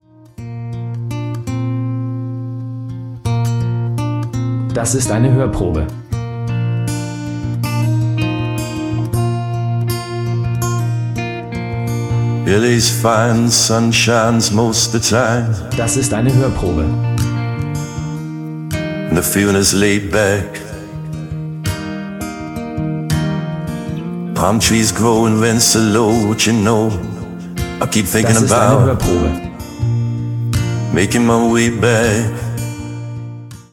Ein Klassiker neu interpretiert!